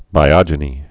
(bī-ŏjə-nē)